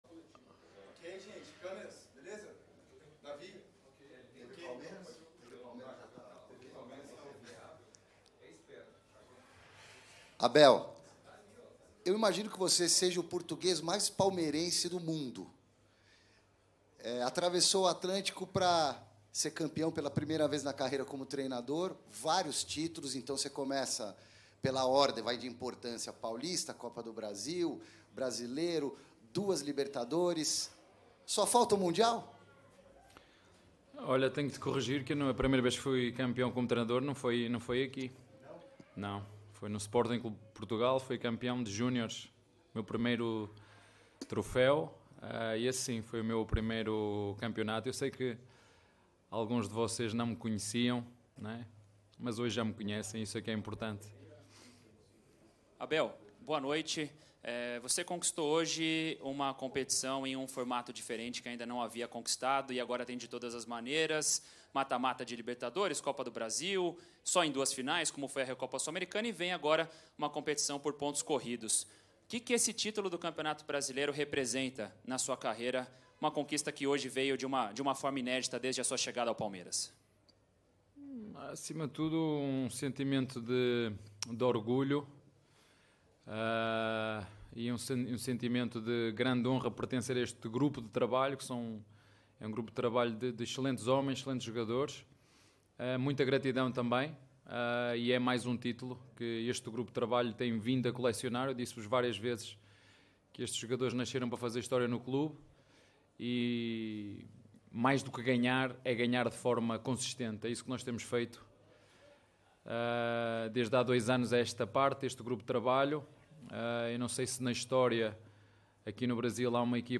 COLETIVA-ABEL-FERREIRA-_-PALMEIRAS-X-FORTALEZA-_-BRASILEIRO-2022.mp3